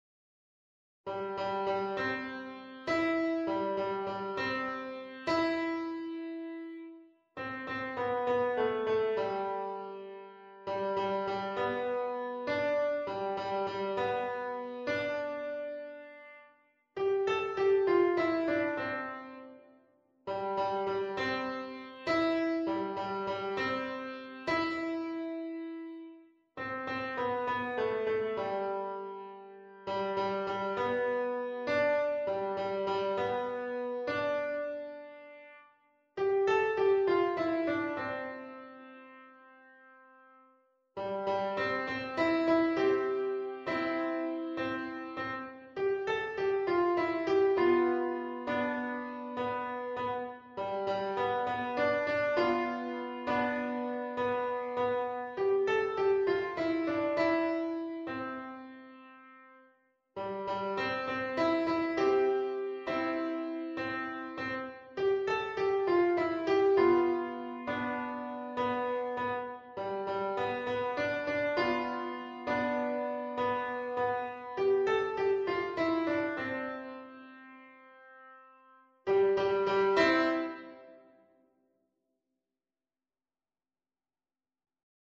No parts available for this pieces as it is for solo piano.
Presto (View more music marked Presto)
4/4 (View more 4/4 Music)
Piano  (View more Beginners Piano Music)
World (View more World Piano Music)